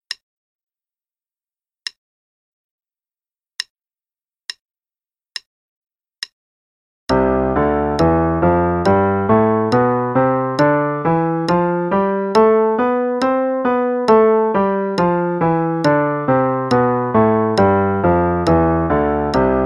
Play-along: C Major Scale (8ths, qn=70)
Play-along_C Major Scale (8ths, qn=70).mp3